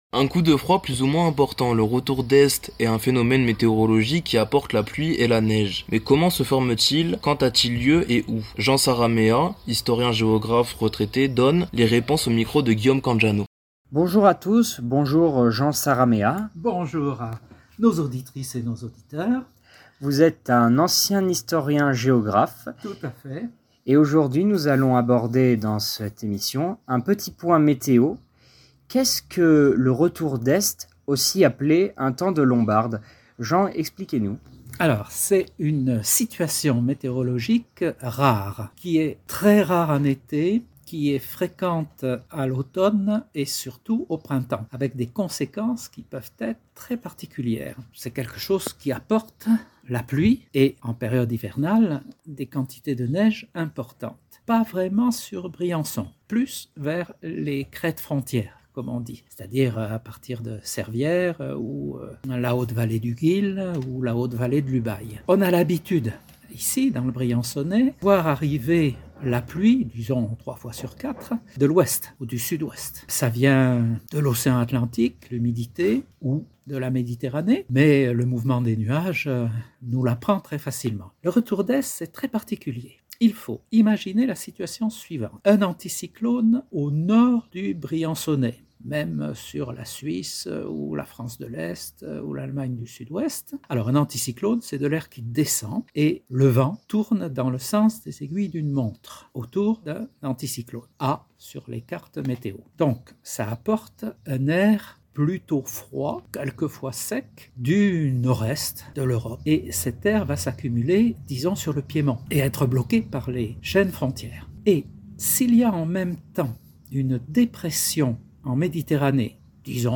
historien-géographe retraité donne les réponses